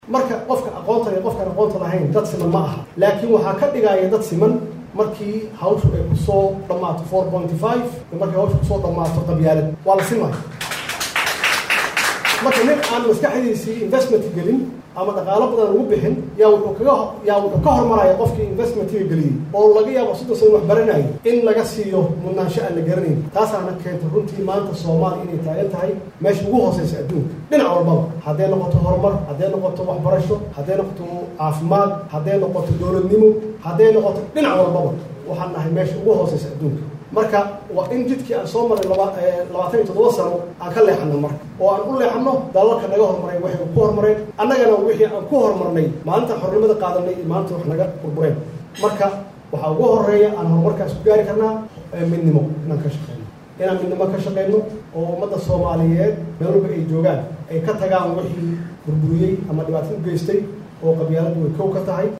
cod-madaxweynaha-1.mp3